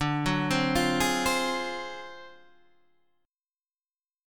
D 7th Sharp 9th Flat 5th